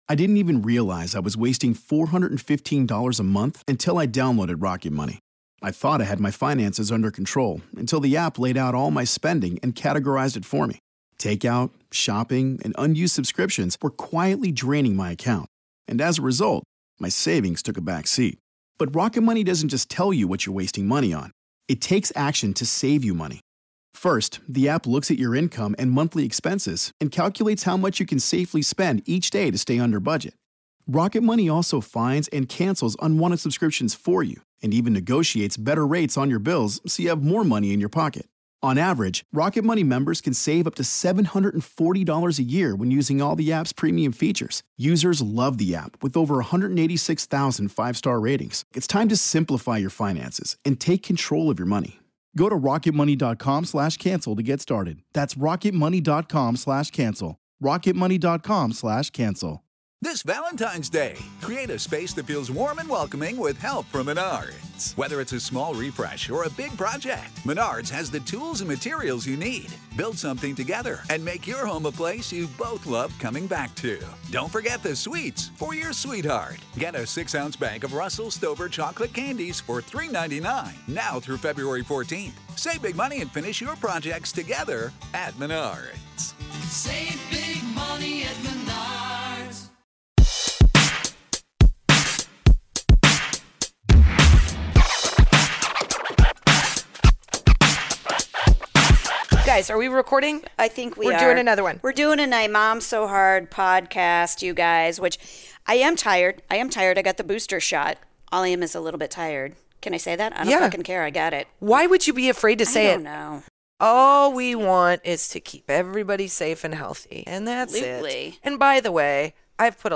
-- ABOUT US: This hilarious comedy podcast about motherhood is for moms by moms talking all about being a mom.
Female comedy duo